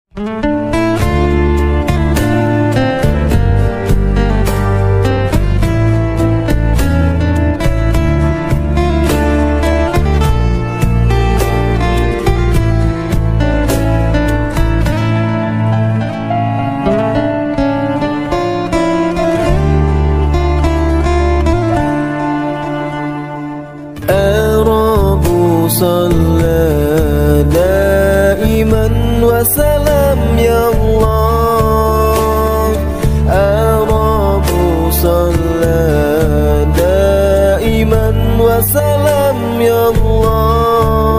sholat berjamaah santri nurul falah sound effects free download
sholat berjamaah santri nurul falah martapura